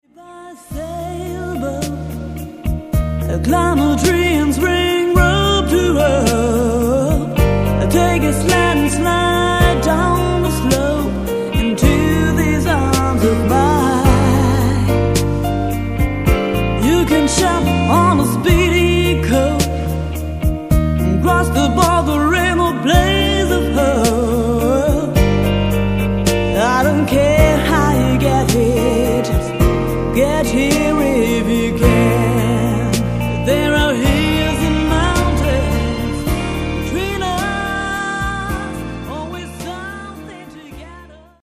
Bass, Guitar, Keys, Vocals
Vocals, Percussion